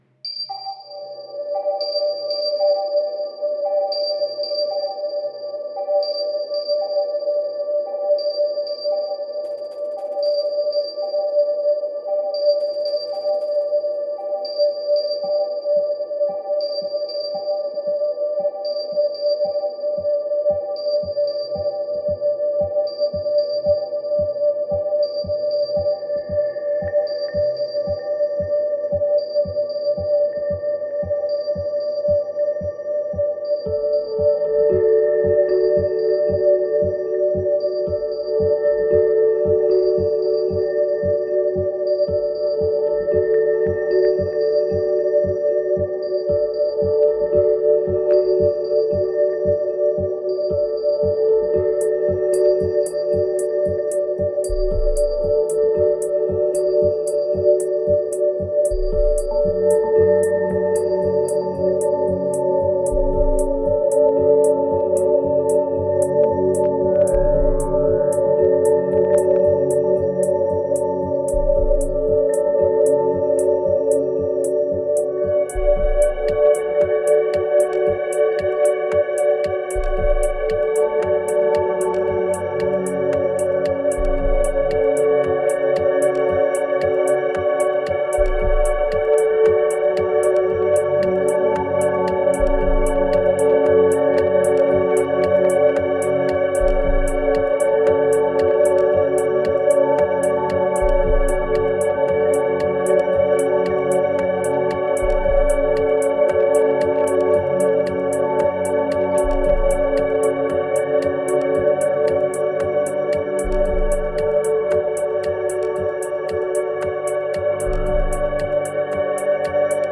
2271📈 - 72%🤔 - 57BPM🔊 - 2015-03-14📅 - 444🌟